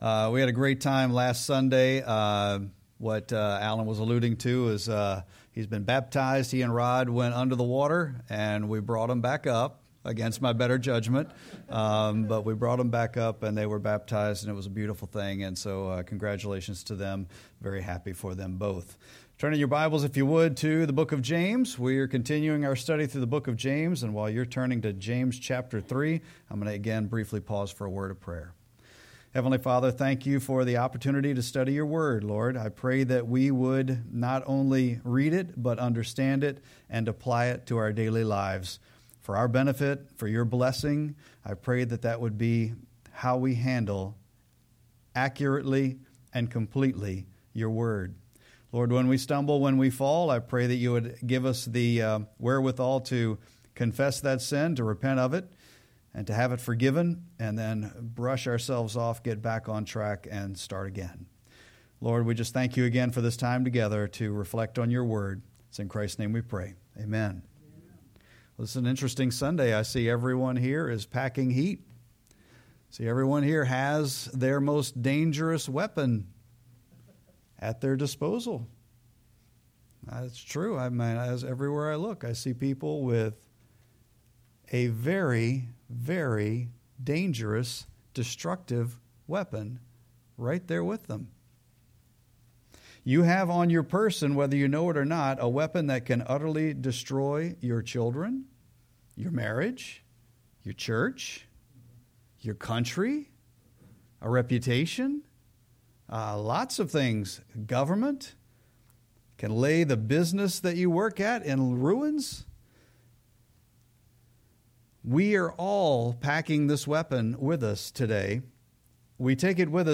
Sermon-7-27-25.mp3